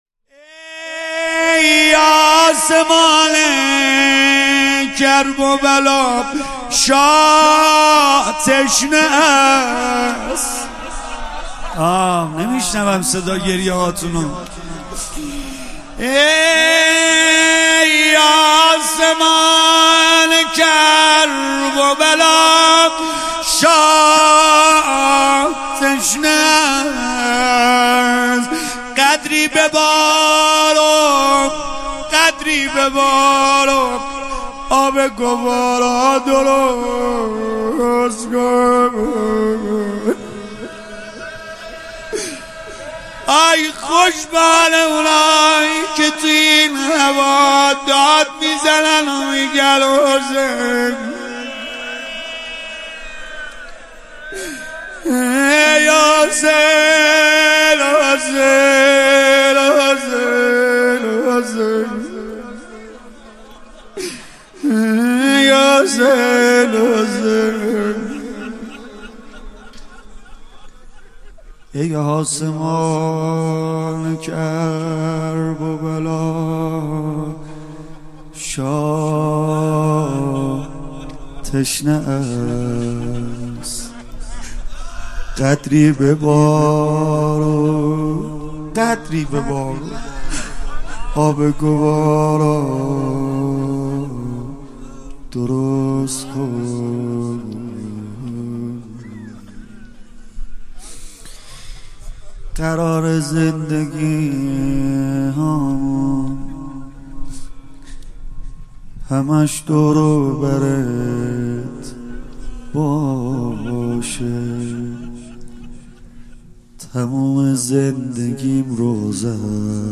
مداحی جدید سید رضا نریمانی شب ششم محرم 1399هیات فداییان حسین(ع) اصفهان